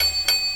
Ride 10.wav